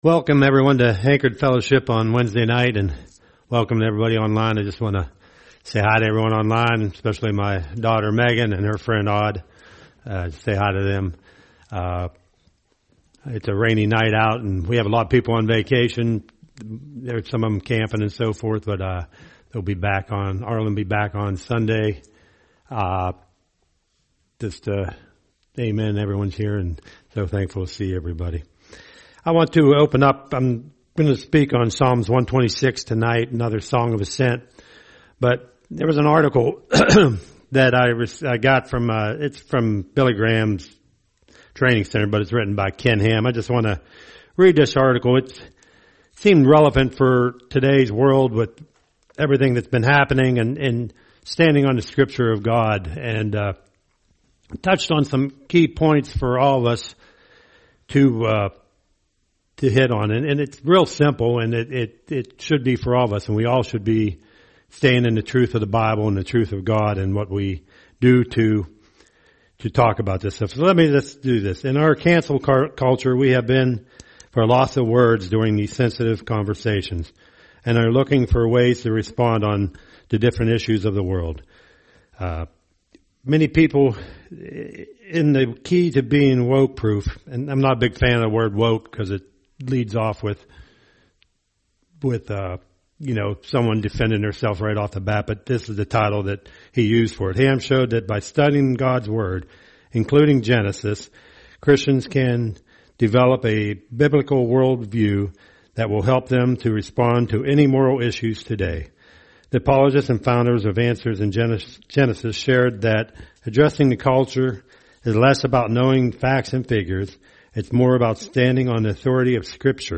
A message from the topics "The Book of Psalms."